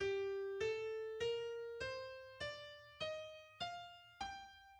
g-mineur
• Natuurlijke mineurtoonladder: G - A - B♭ - C - D - E♭ - F - G